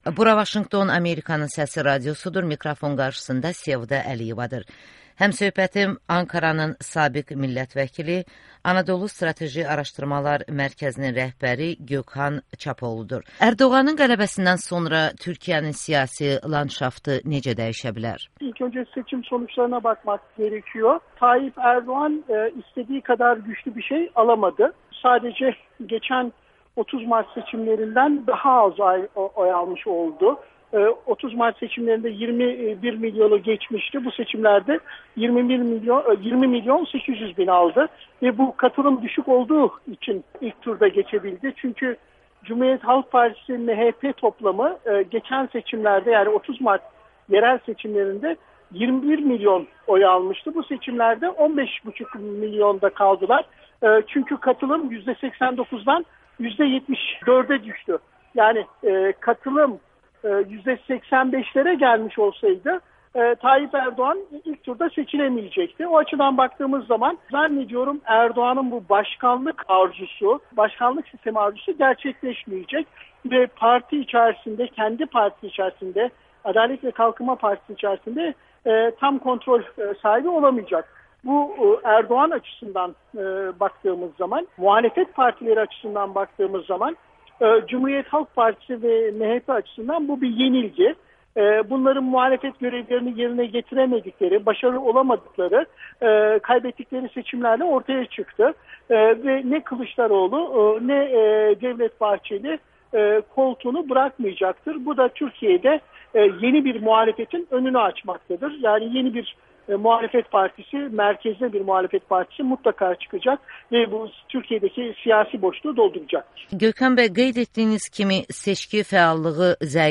Anadolu Strateji Araşdırmalar Mərkəzinin rəhbəri Gökhan Çapoğlu ilə müsahibə